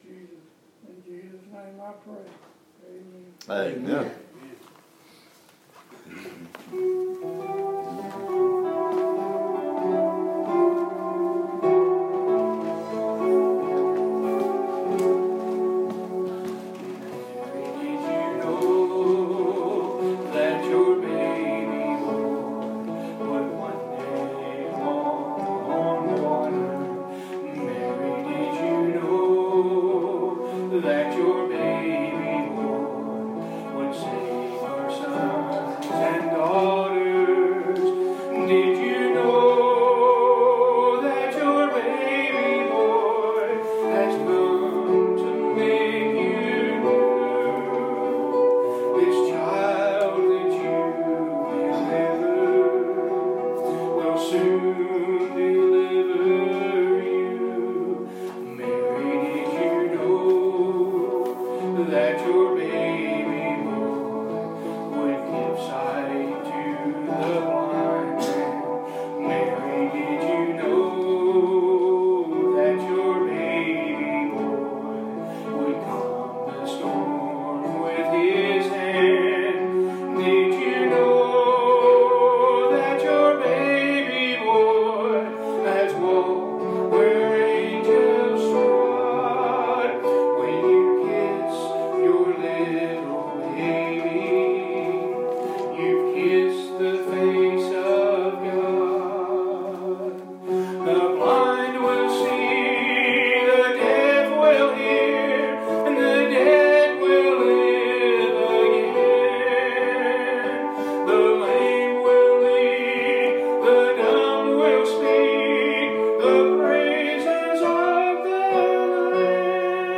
Christ Is Christmas Series Sermon Notes: Luke 2:8-14 (KJV) And there were in the same country shepherds abiding in the field, keeping watch over their flock by night.